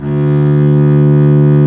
Index of /90_sSampleCDs/Roland - String Master Series/STR_Cb Bowed/STR_Cb3 Arco nv
STR DBLBAS02.wav